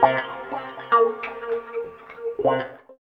90 GTR 2  -L.wav